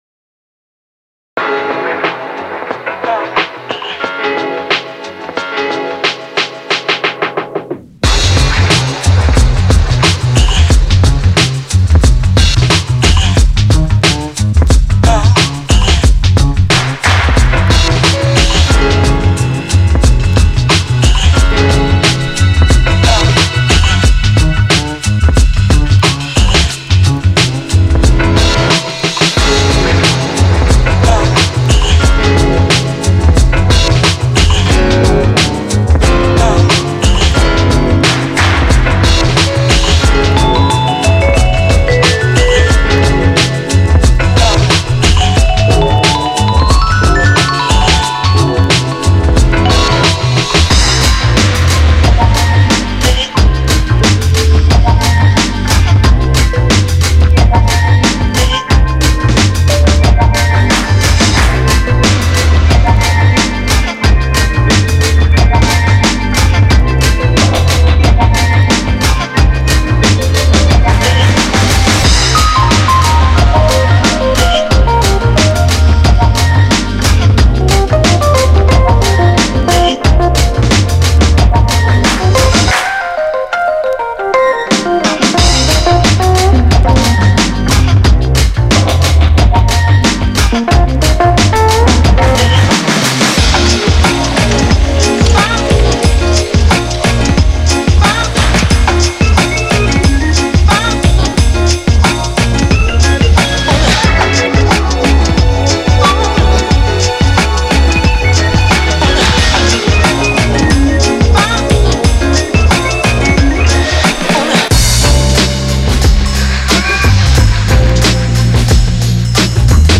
Genre:Soul and RnB
このパックでは、重厚なストリートビートと甘くジャジーなジャムが融合したソウルフルなサウンドをお届けします。
デモサウンドはコチラ↓